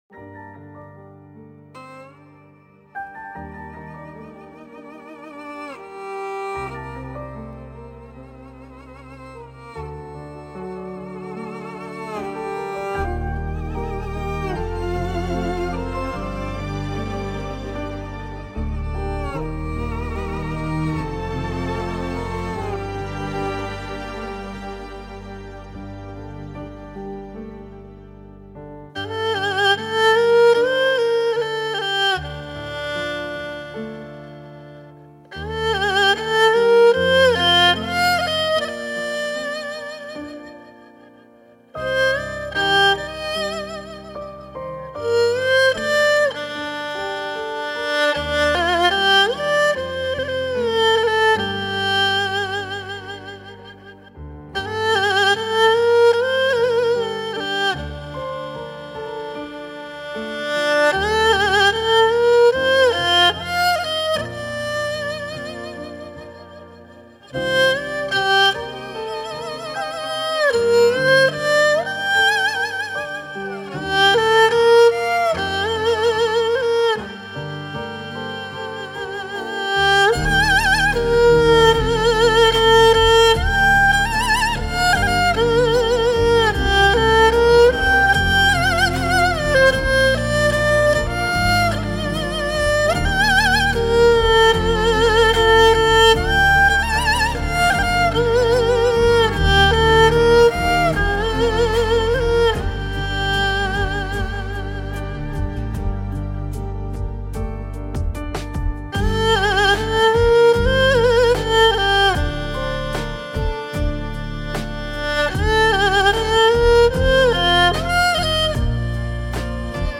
乐器：二胡